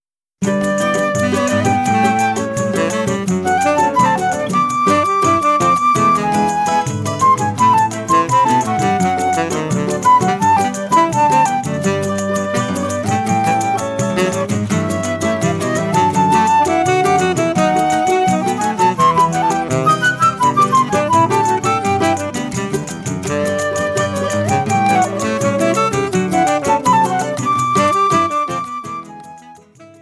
– Full audio with choro ensemble, solo and counterpoint.
flute
tenor saxophone